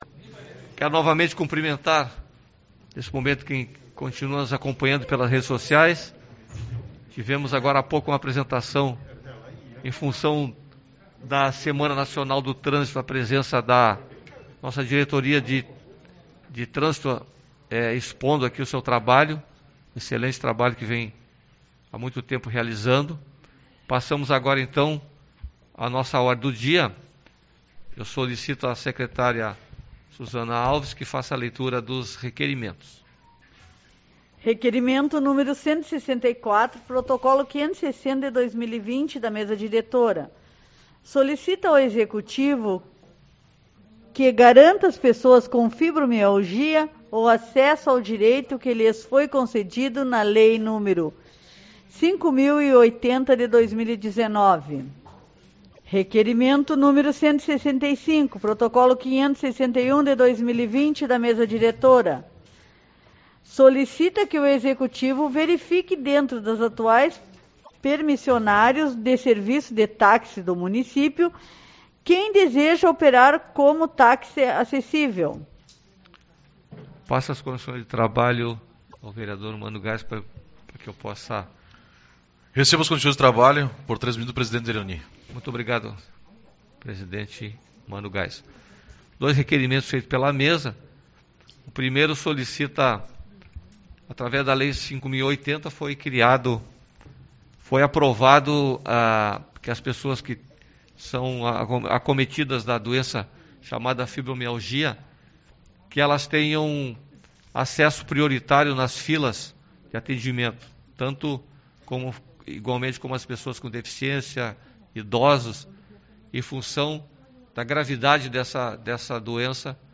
22/09 - Reunião Ordinária